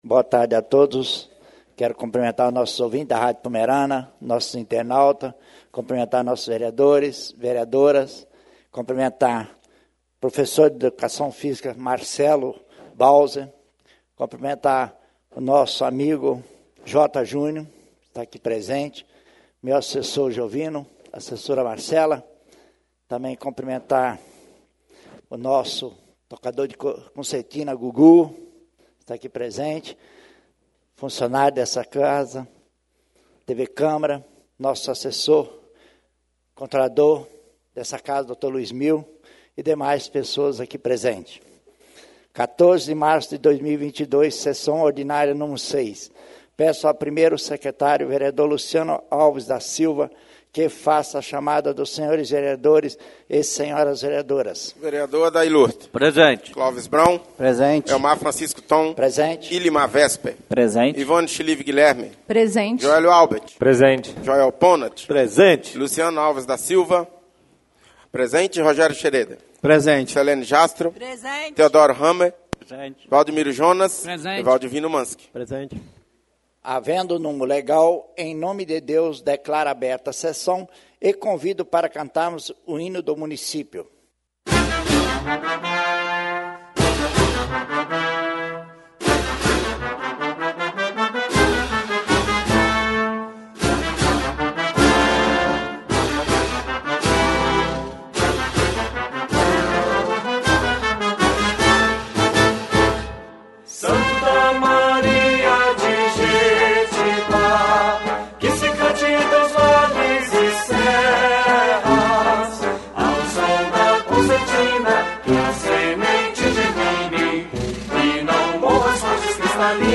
SESSÃO ORDINÁRIA Nº 6/2022